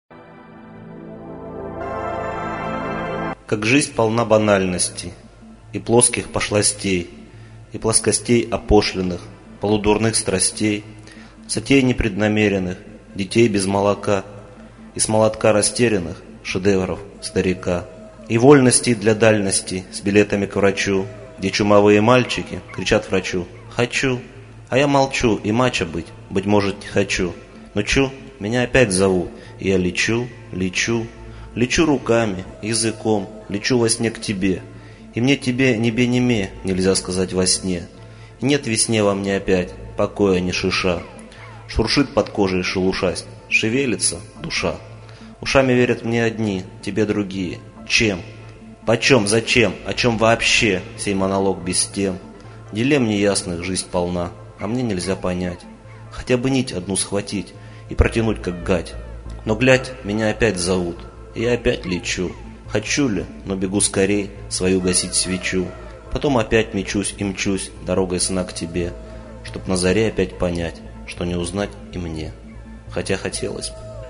19.Не узнать и мне (о вне)(стих)